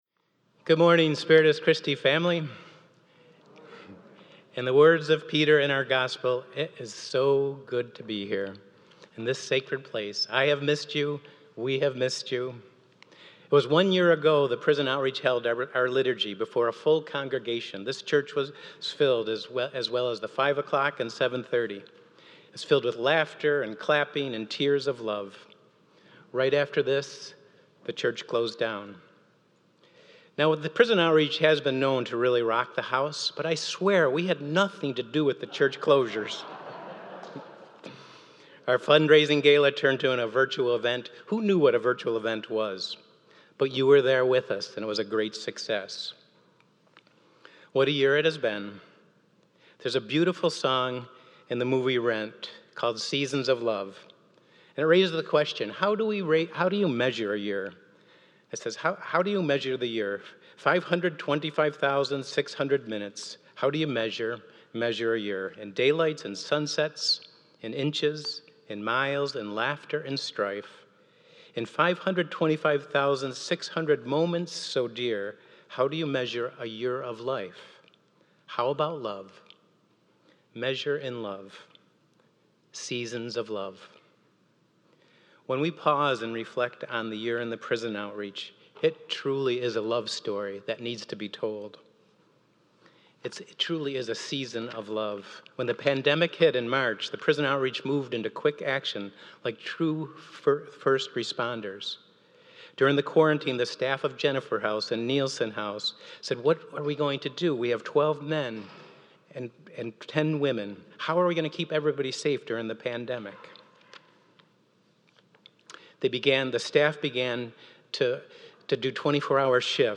Prison Outreach Liturgy 2021